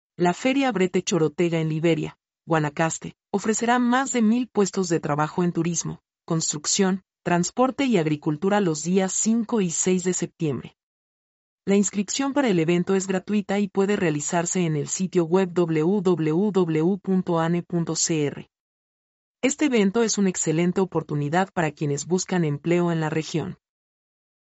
mp3-output-ttsfreedotcom-98-1.mp3